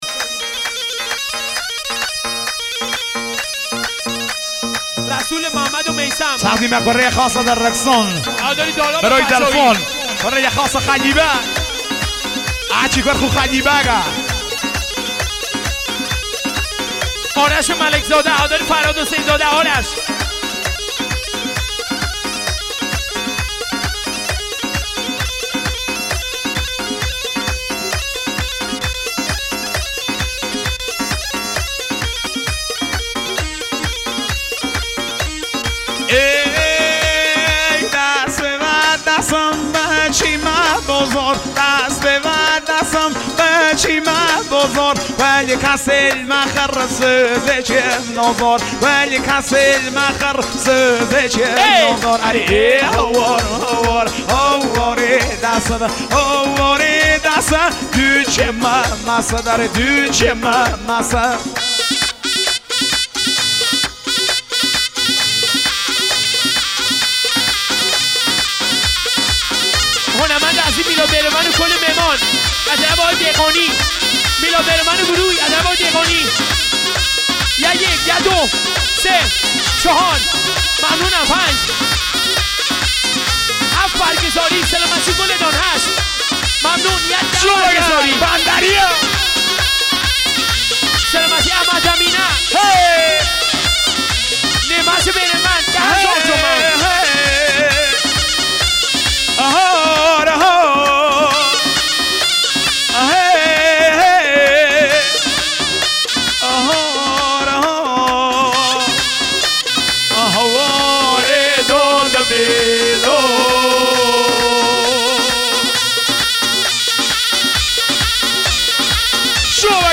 اهنگ شاد لکی و لری با ارگ